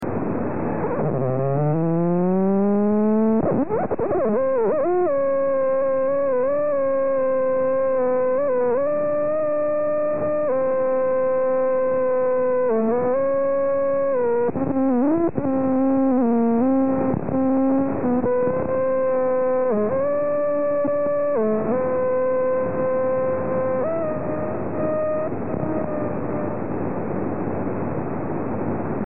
Le registrazioni originali su cassette sono tuttora conservate nel mio archivio, e le digitalizzazioni degli anni 1993-1995 sono state effettuate con software buoni sebbene non eccelsi, e a risoluzioni "decenti" a 44Khz.